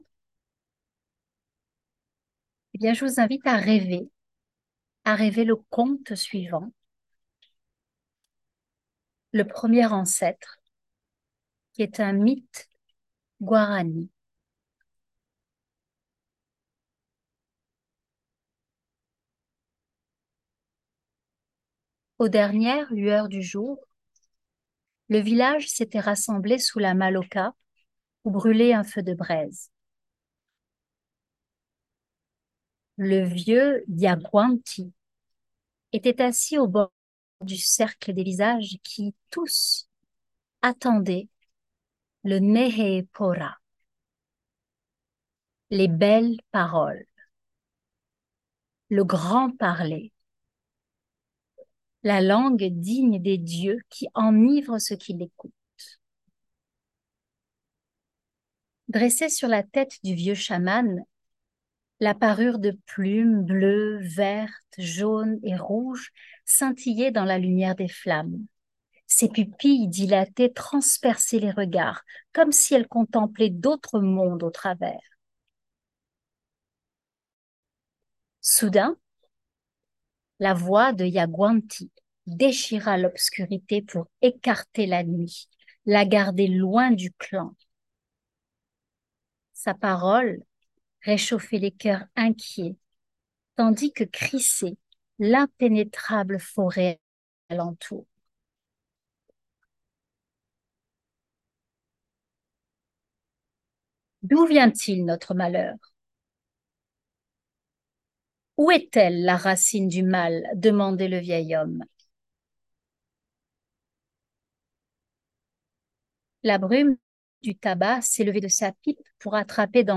En cette période de fête où les plus jeunes seront, n’en doutons pas, les plus gâtés, je pense à vous les adultes et je vous offre en cadeau la lecture d’un conte issu de la sagesse amazonienne qui s’intitule Le premier ancêtre.